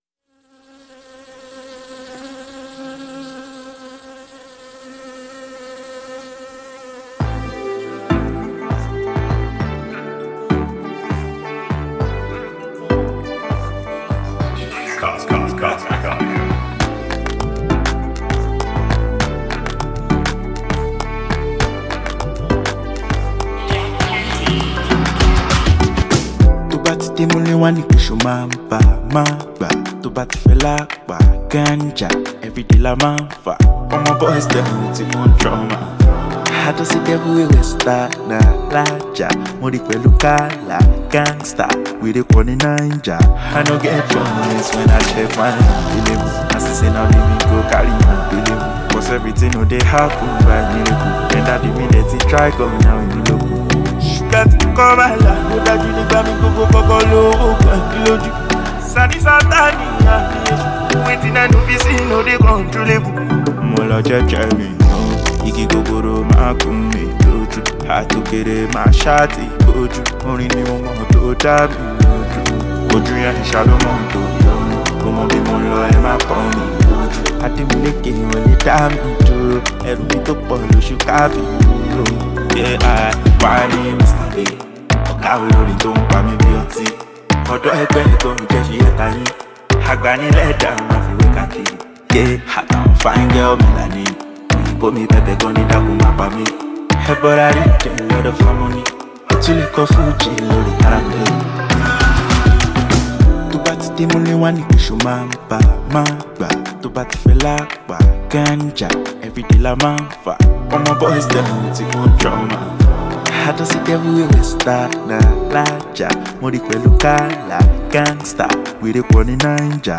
It’s an uplifting song that helps you escape everyday life.